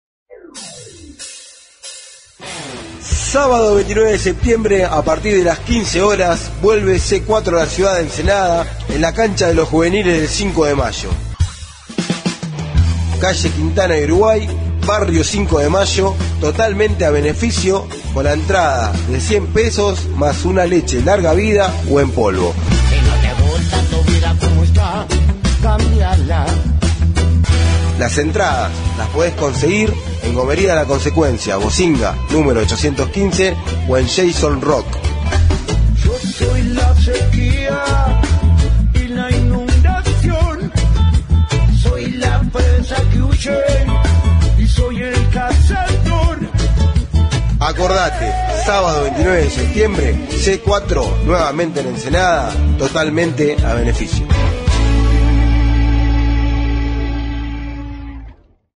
REGGAE COMBATIVO
suena a reggae con líricas combativas, indispensables en esta música, y también tiene sabor a barrio y a rock.